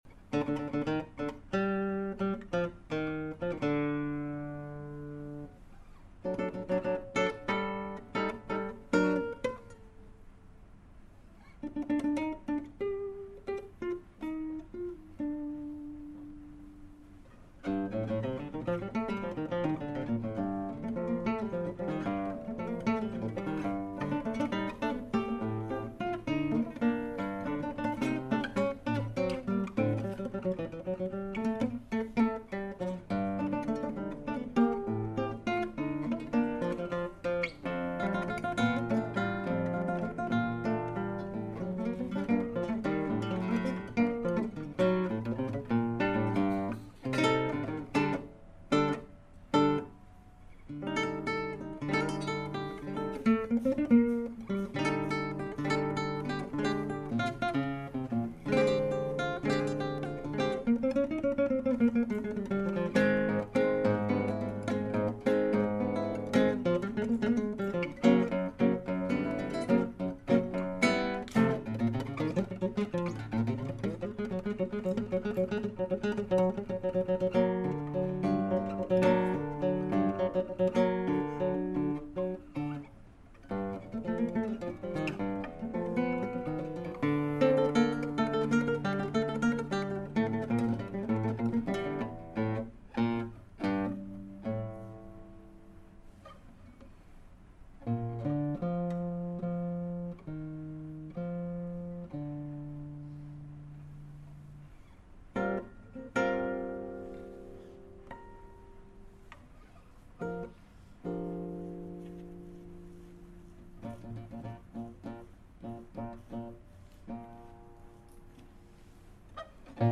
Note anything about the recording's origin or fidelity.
Mp3 recording from an in-class performance